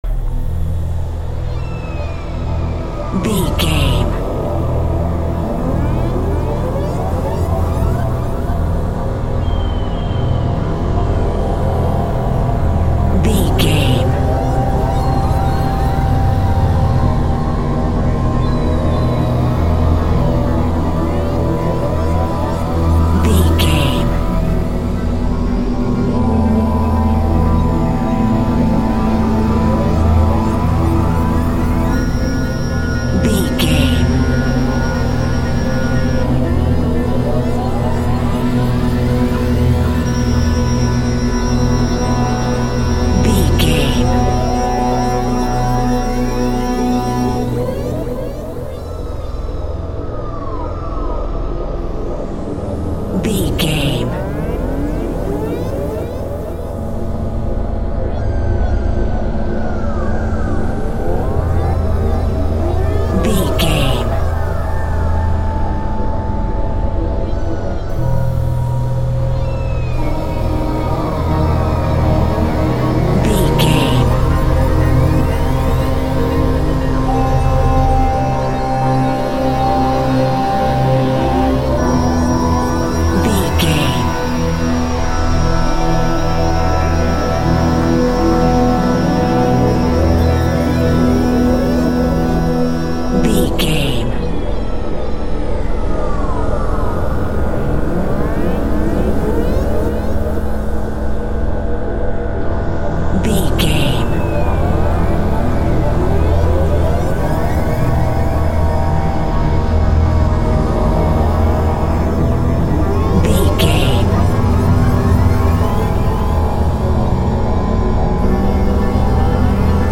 Aeolian/Minor
A♭
scary
ominous
dark
suspense
haunting
eerie
percussion
synths
Synth Pads
atmospheres